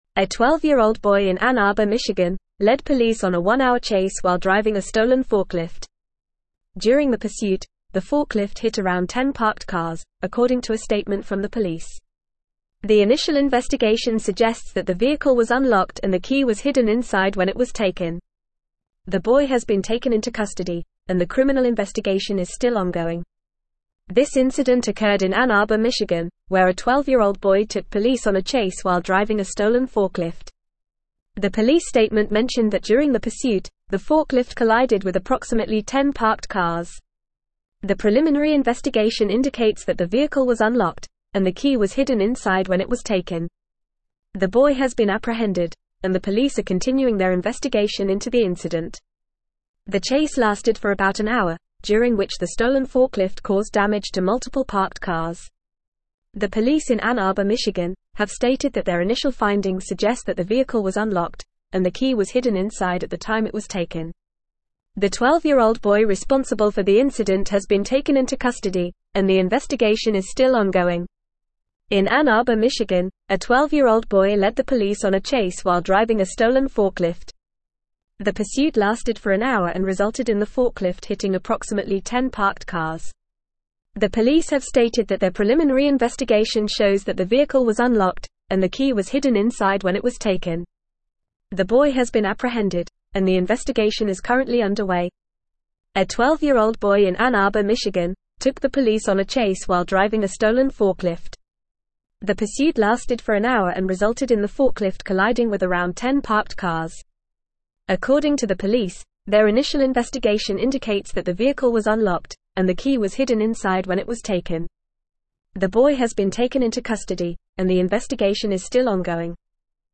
Fast
English-Newsroom-Advanced-FAST-Reading-12-Year-Old-Boy-Steals-Forklift-Leads-Police-on-Chase.mp3